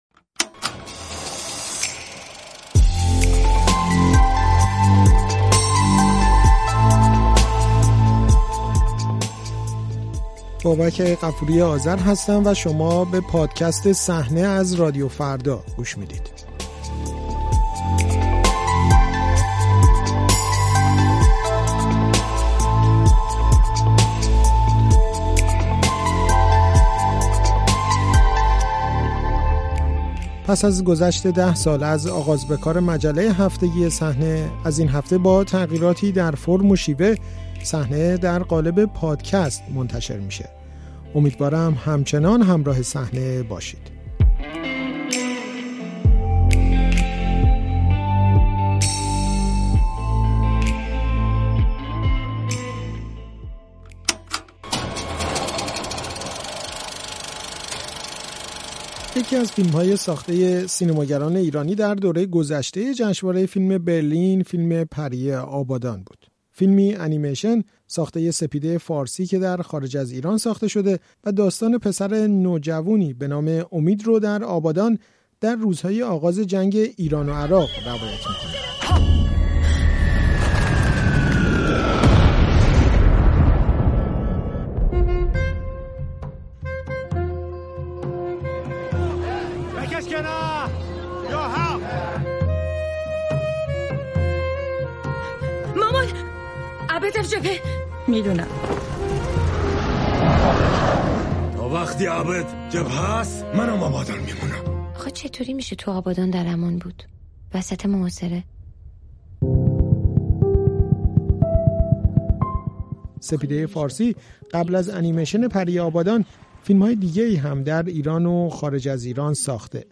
سپیده فارسی در گفت‌وگو با پادکست «صحنه» از فیلم «پری آبادان» می‌گوید.